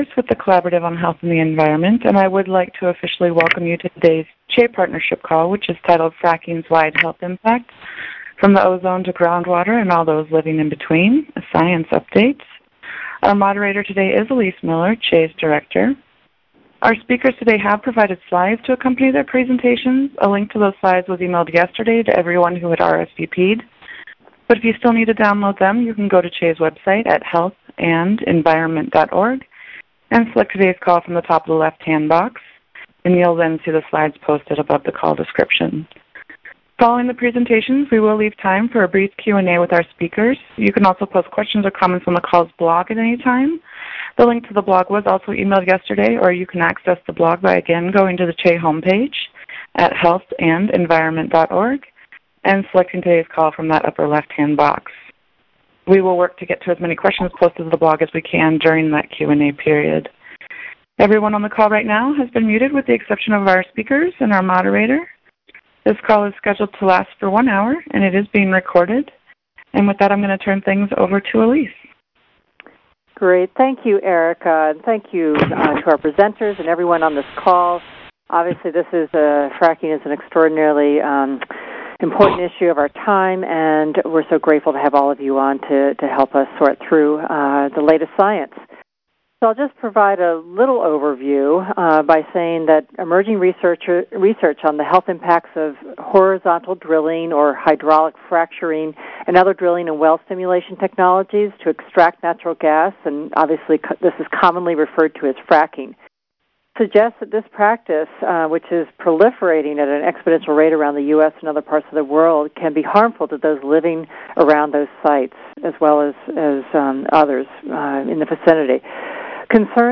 Here’s a recording of the call.